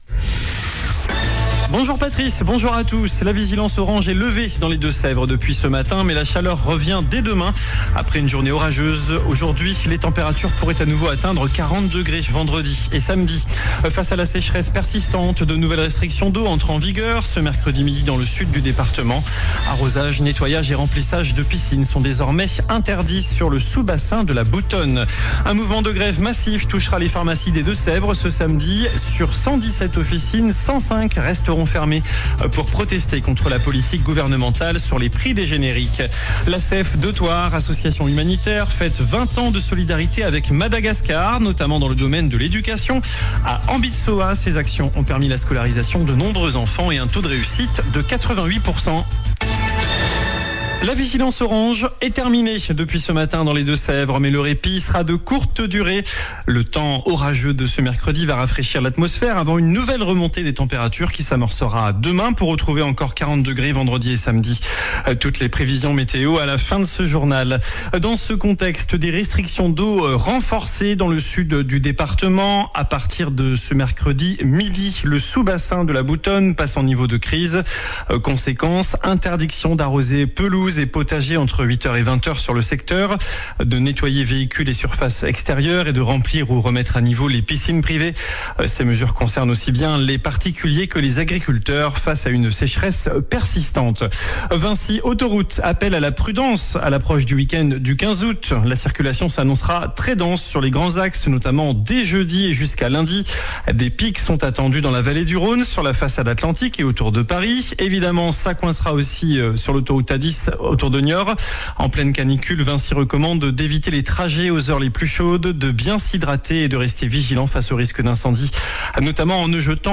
JOURNAL DU MERCREDI 13 AOÛT ( MIDI )